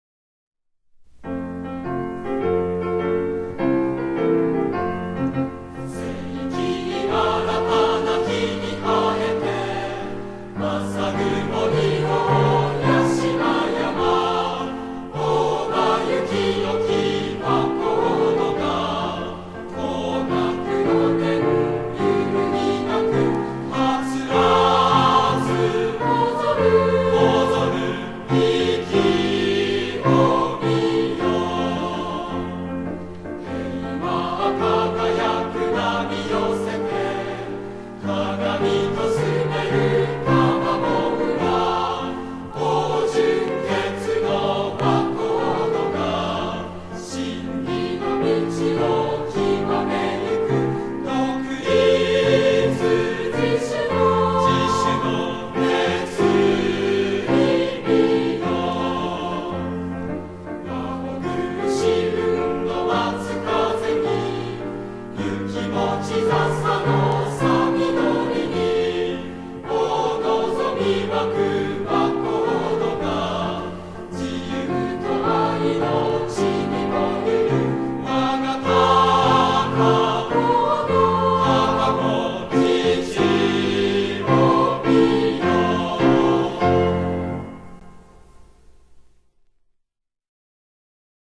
校歌